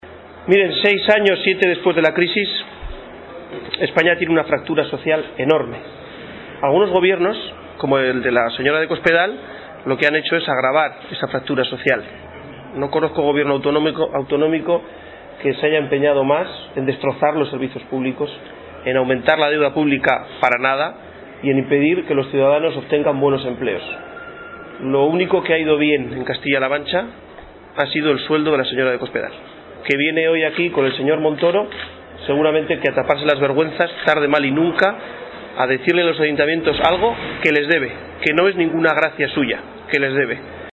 En la consecución de ese objetivo y de conectar con los ciudadanos, Luena participó en Ciudad Real en una asamblea abierta junto al secretario general del PSOE en la provincia, José Manuel Caballero, y la candidata a la Alcaldía de la capital, Pilar Zamora.